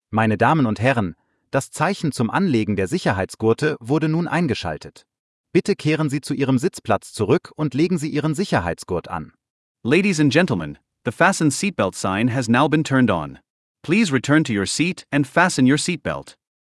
FastenSeatbelt.ogg